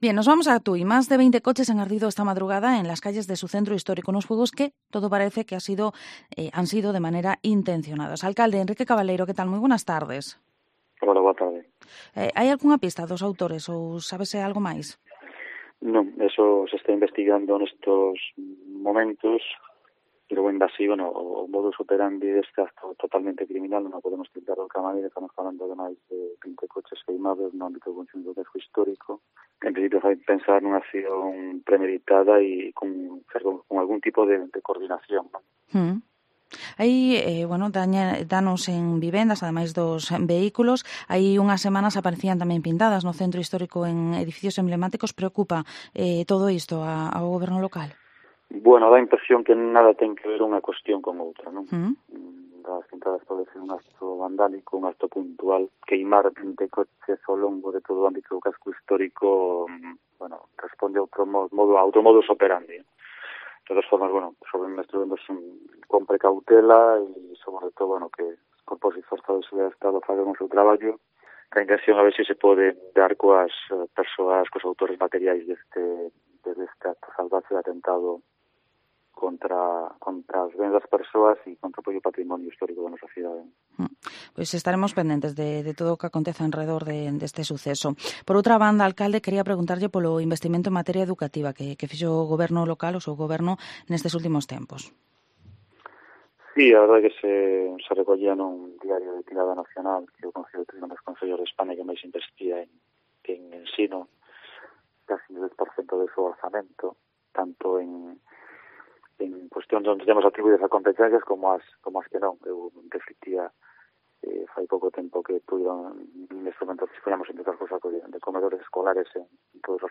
Entrevista al Alcalde de Tui, Enrique Cabaleiro